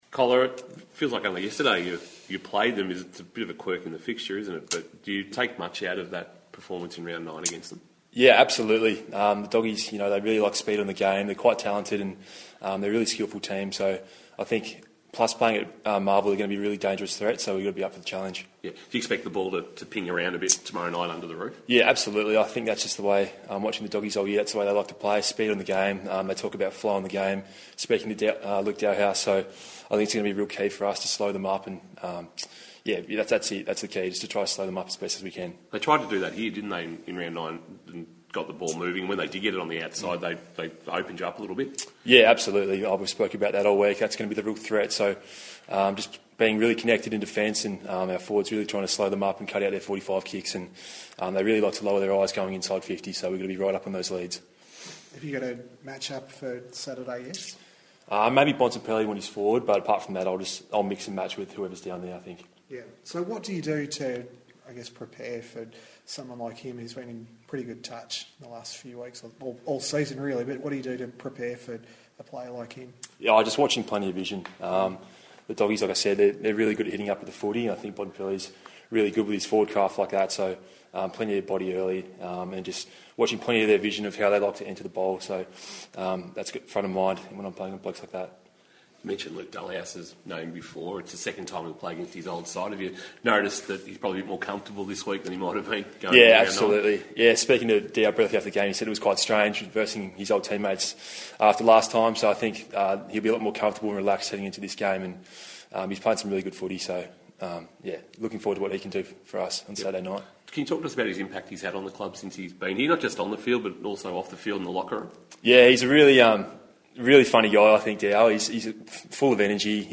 Geelong defender Jake Kolodjashnij faced the media ahead of Saturday's clash with the Western Bulldogs.